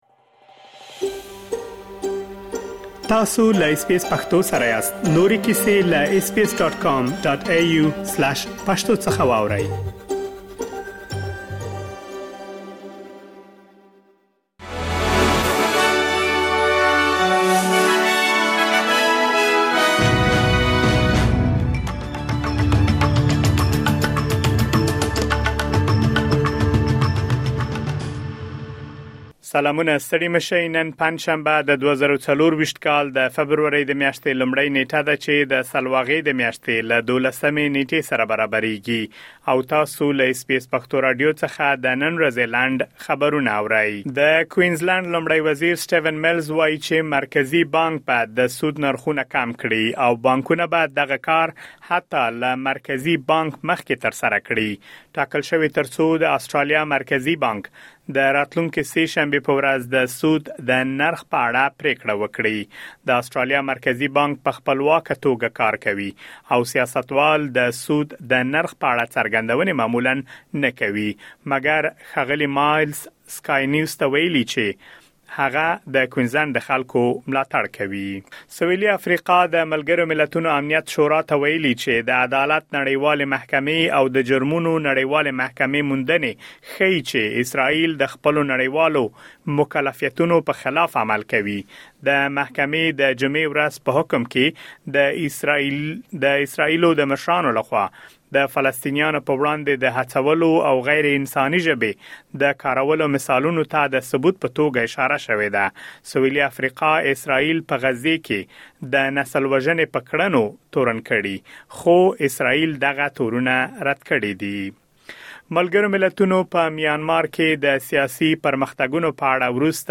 د اس بي اس پښتو راډیو د نن ورځې لنډ خبرونه |۱ فبروري ۲۰۲۴